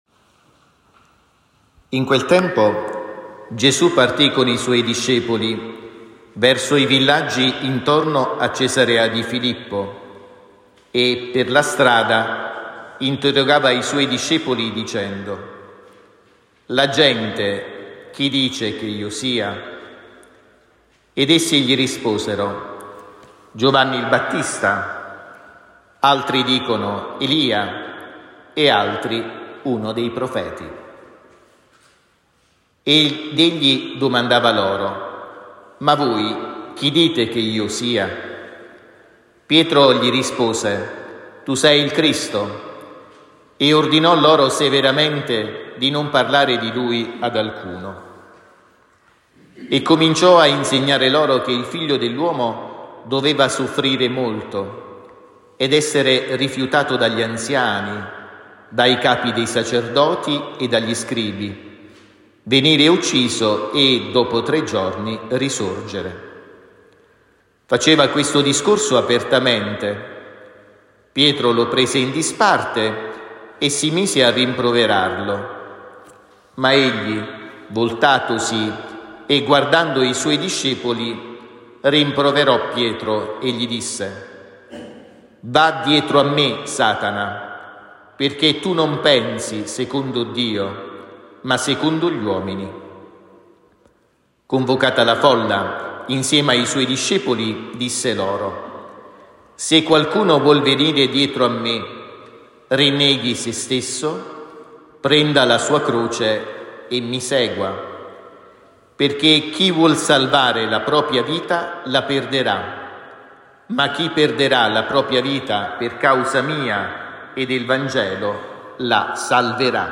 XXIV DOMENICA DEL TEMPO ORDINARIO (ANNO B) – 15 settembre 2024